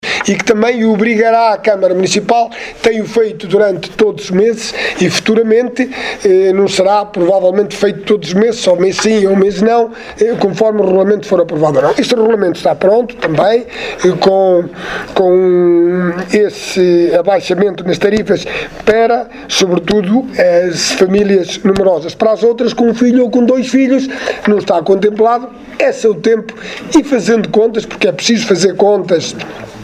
O novo regulamento de Águas e resíduos sólidos da Câmara Municipal de Sátão que entra este ano em vigor trará mais responsabilidades quer para o município quer para o munícipe, mas a novidade é a redução das tarifas para as famílias com três ou mais filhos, como referiu Alexandre Vaz, presidente da Câmara Municipal de Sátão.